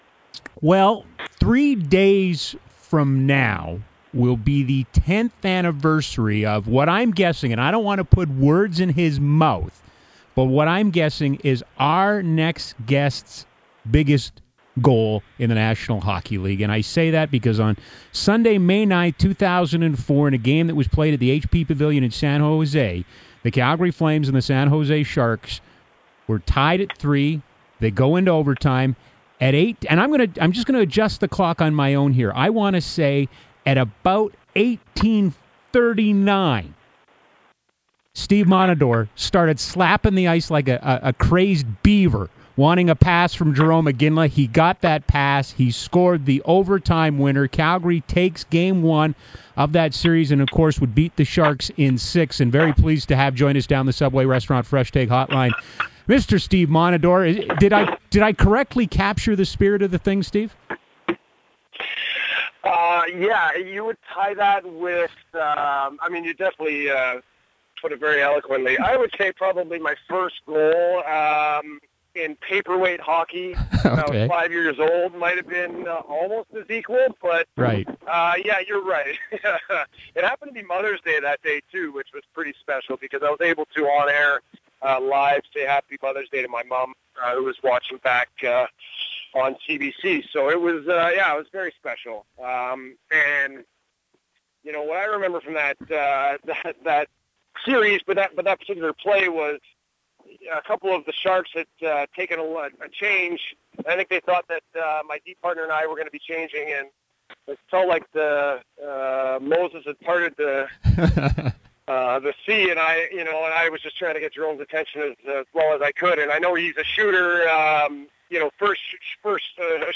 Steve Montador FAN960 Radio Interview - Calgarypuck Forums - The Unofficial Calgary Flames Fan Community